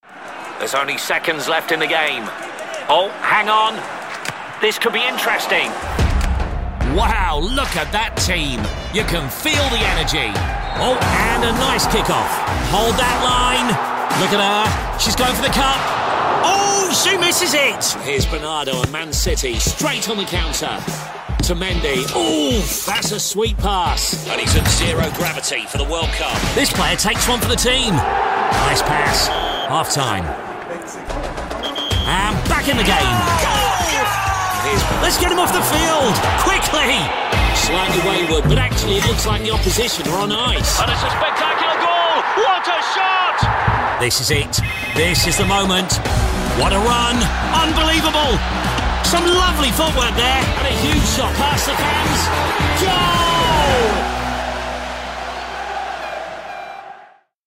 The UK’s Leading Football Commentator Voice
Looking for a high-energy, big-match commentator voice?
If you like this style, you might also enjoy my Movie Trailer, Gameshow Host or even my Voice of God reads - all delivered from my broadcast-quality studio.
From one-liners to full campaign coverage, you get consistency, clarity and that instantly recognisable football commentator sound.
If you want the drama, the urgency, and the iconic British tone — you’re in the right place.